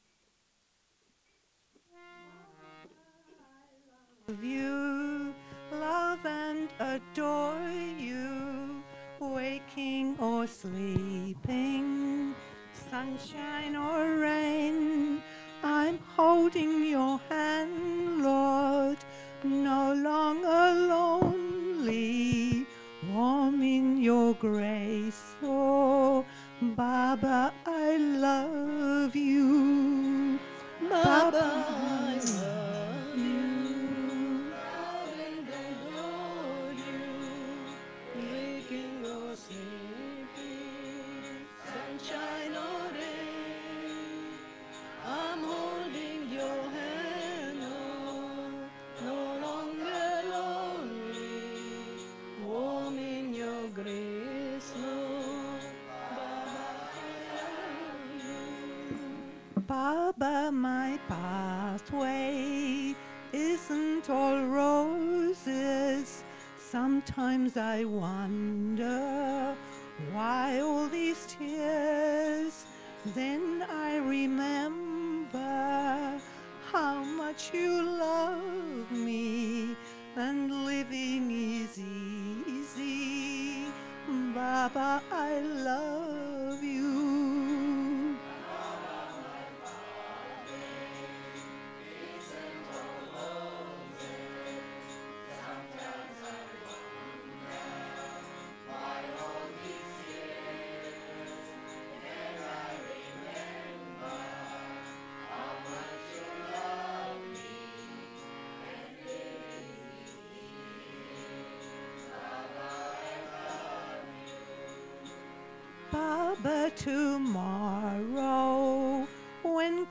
1. Devotional Songs
Major (Shankarabharanam / Bilawal)
8 Beat / Keherwa / Adi
6 Pancham / A
3 Pancham / E
Lowest Note: p / G (lower octave)
Highest Note: M1 / F